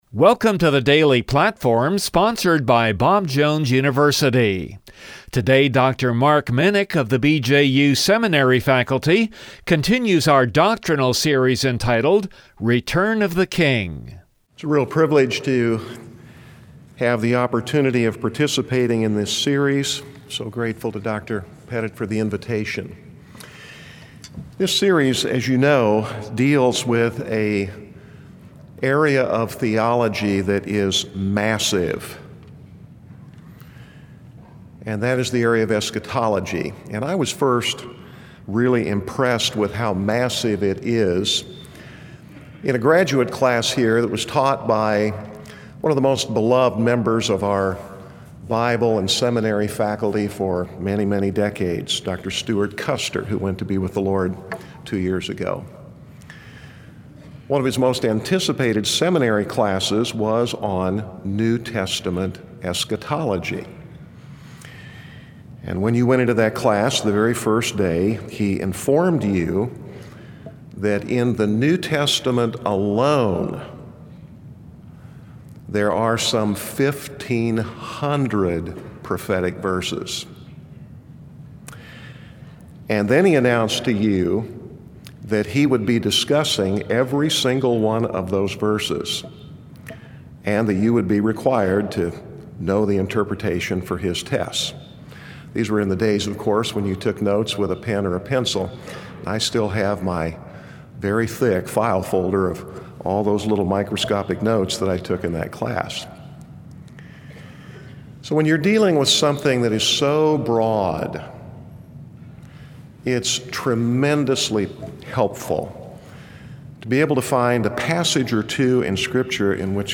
God’s Message to the Nations Psalm 2 From the chapel service on 03/06/2019 Download Share this Post